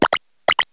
tandemaus_ambient.ogg